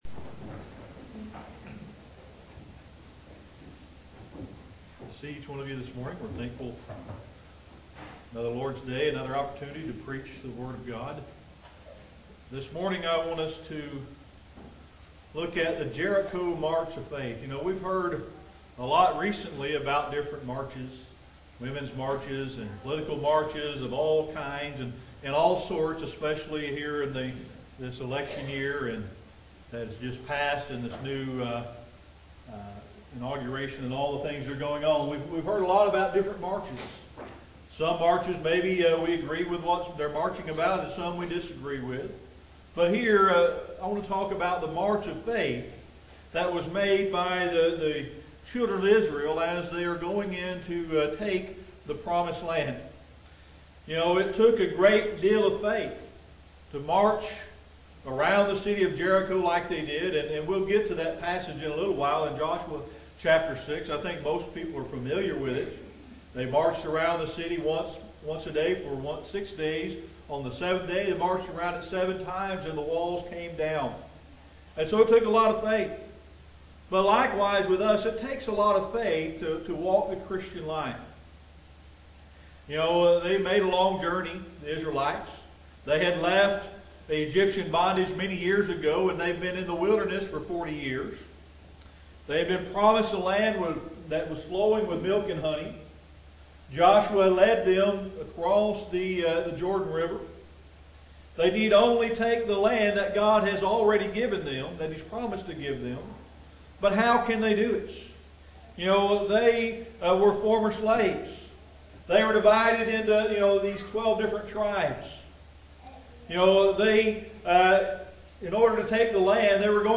Audio Sermon: The Jericho March of Faith